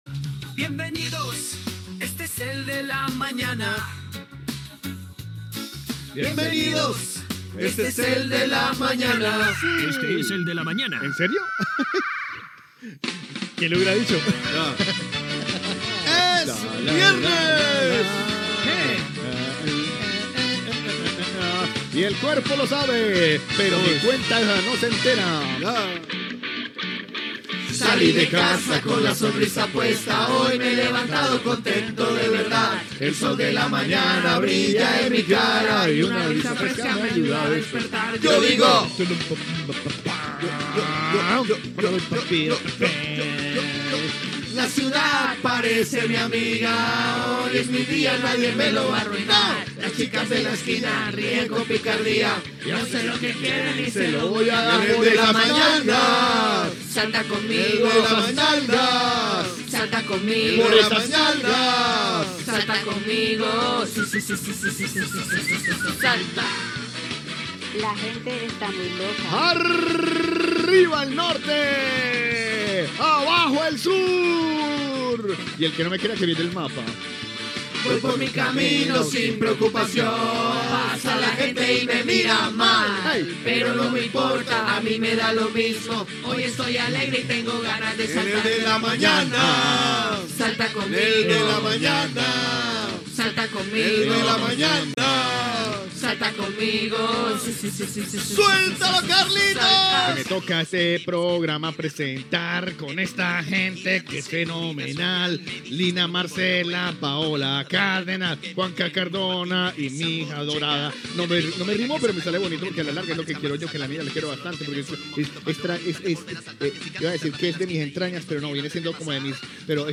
Dues cançons inicials cantades per l'equip del programa
cantat per la veu sintètica
Gènere radiofònic Entreteniment